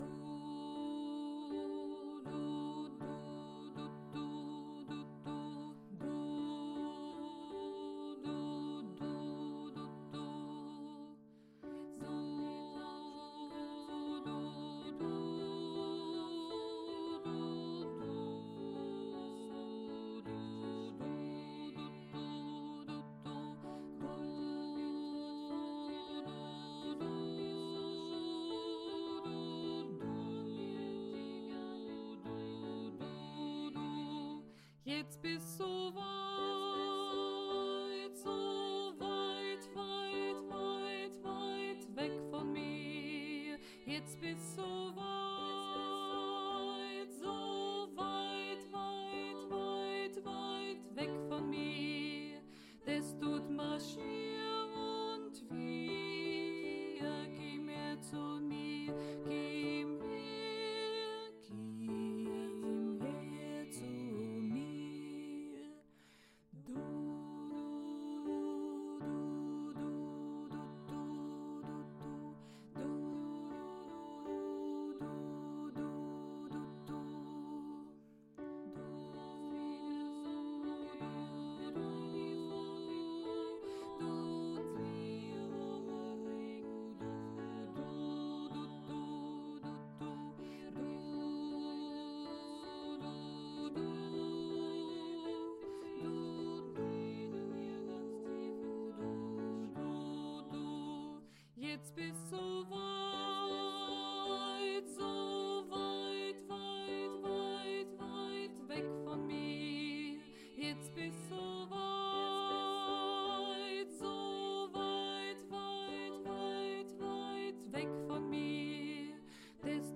Weit weit weg – Bass
aweitwegbass.mp3